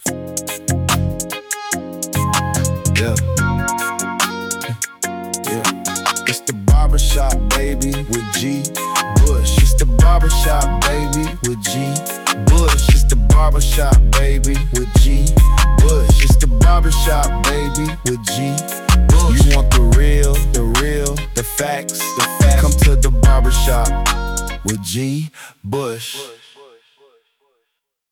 Intro Music
{ Company Jingle } April 2026